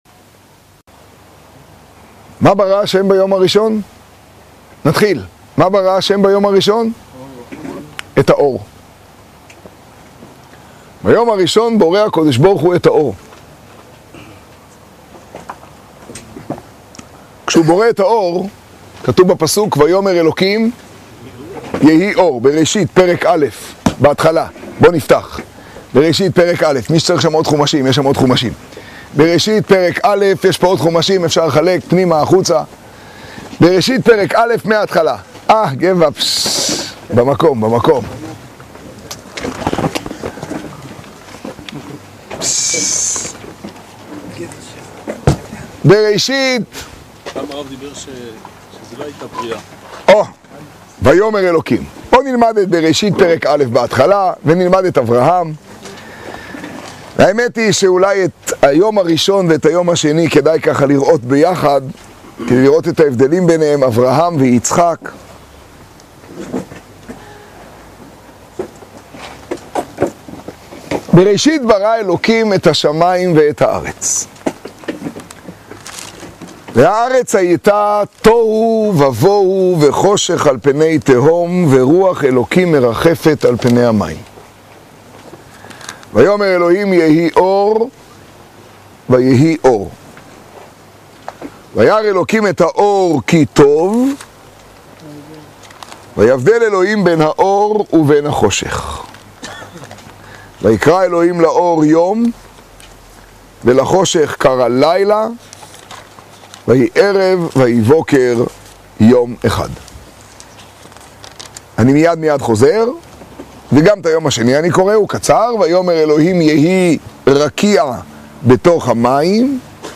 השיעור במגדל, הושענא רבה תשעה.